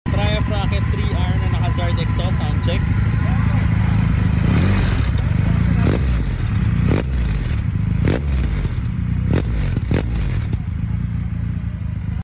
Soundchek tayo! Triumph rocket 3r